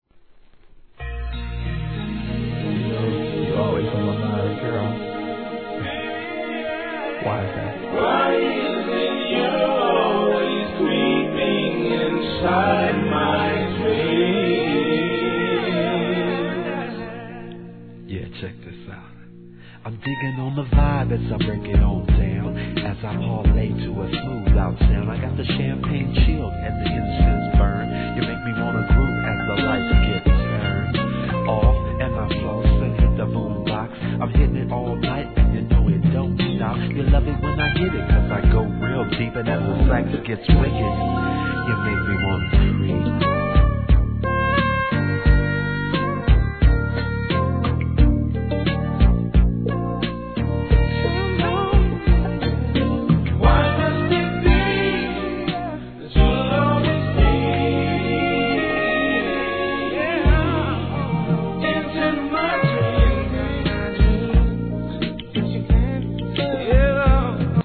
HIP HOP/R&B
SMOOTH JAZZ SAX奏者による1992年のアーバンなR&B作品!!